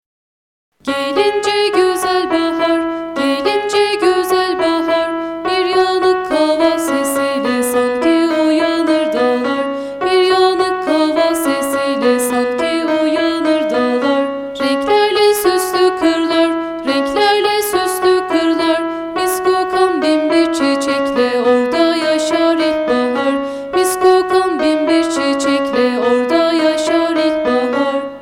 çocuk şarkısı mp3 sitemize eklenmiştir.